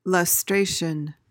PRONUNCIATION:
(luhs-TRAY-shuhn)